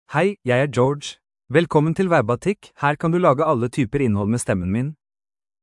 George — Male Norwegian Bokmål AI voice
George is a male AI voice for Norwegian Bokmål (Norway).
Voice sample
Listen to George's male Norwegian Bokmål voice.
Male